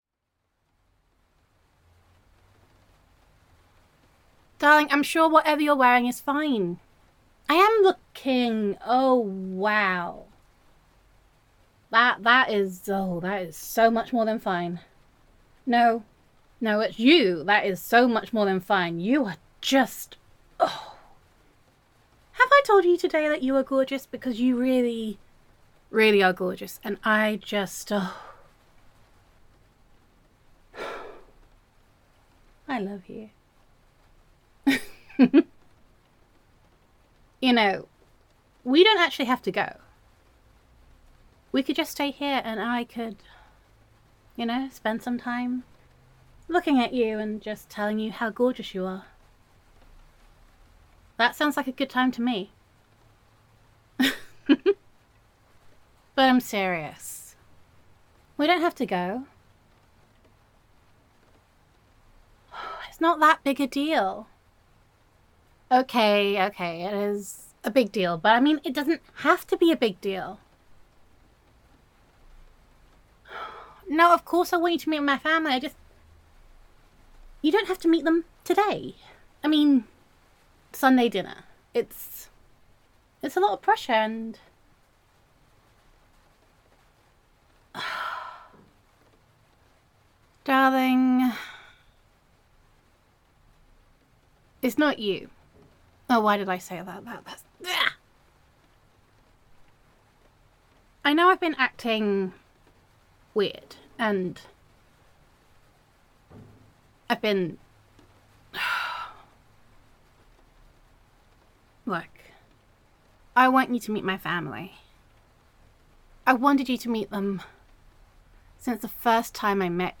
[F4A] Sunday Dinner
[Girlfriend Roleplay]